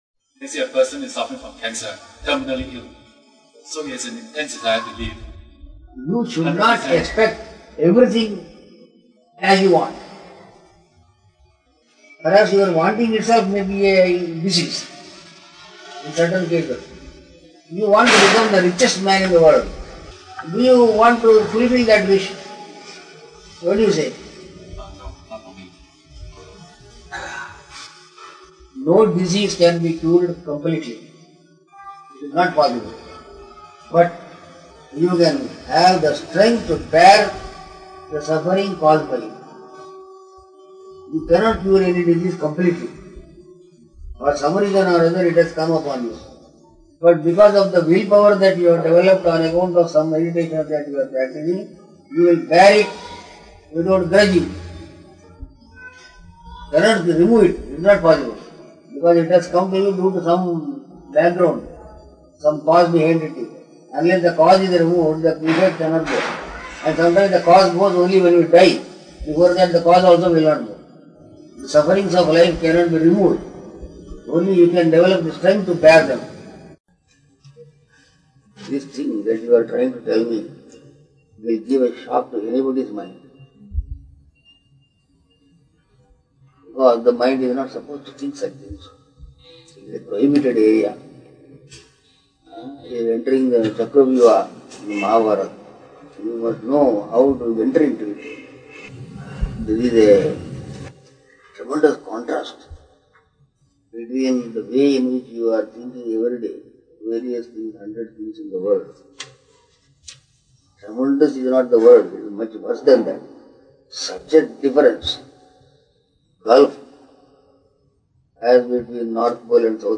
(Darshan given on December 14th, 1995.)